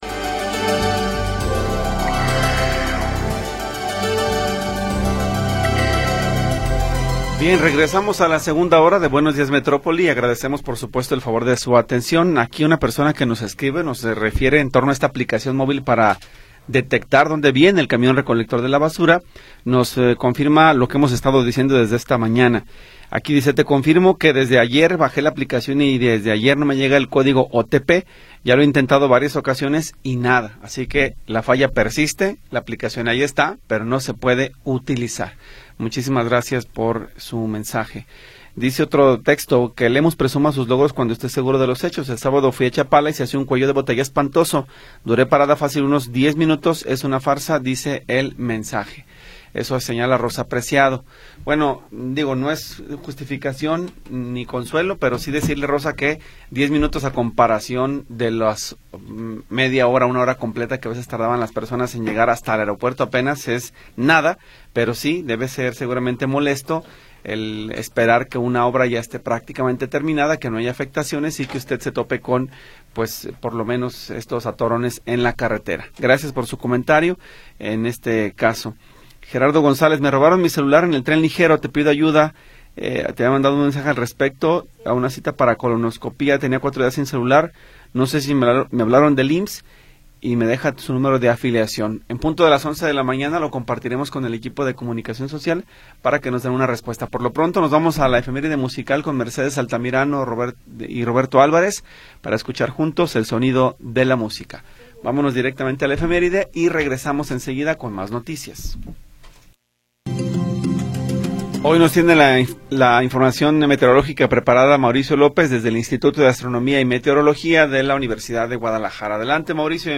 Información oportuna y entrevistas de interés
Segunda hora del programa transmitido el 24 de Noviembre de 2025.